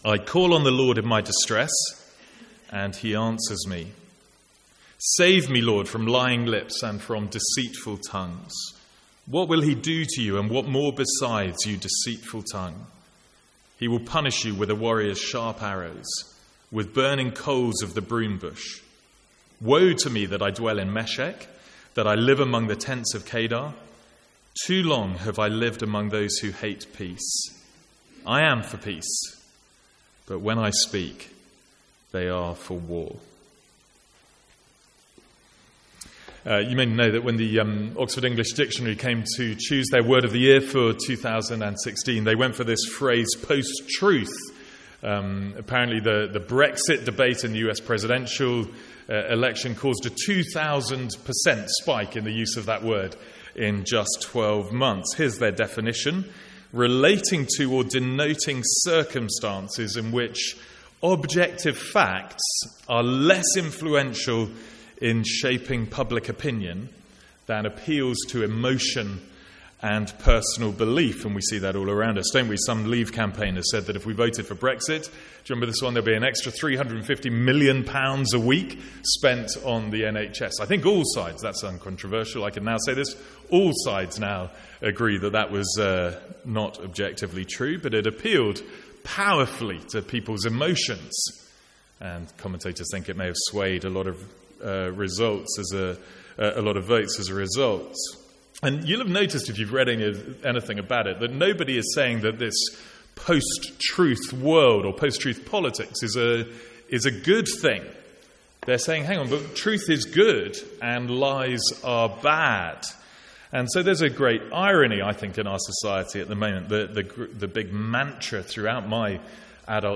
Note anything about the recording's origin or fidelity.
From the Sunday morning series in Psalms.